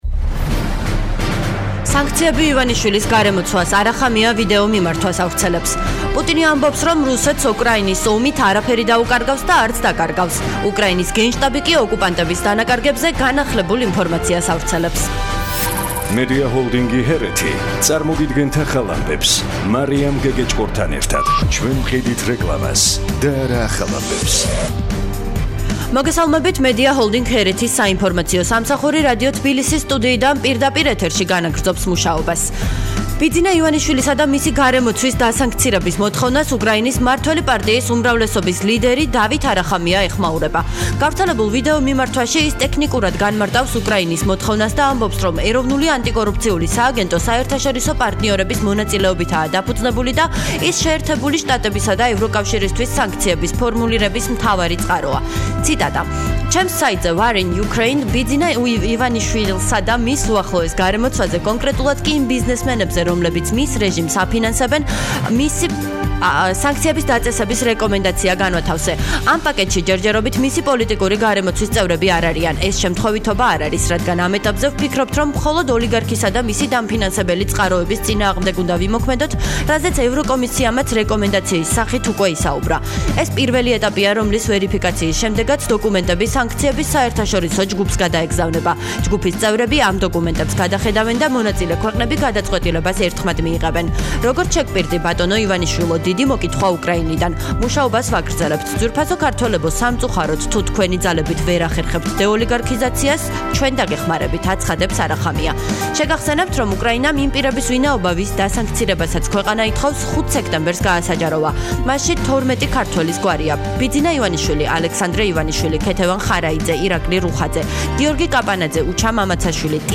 ახალი ამბები 15:00 საათზე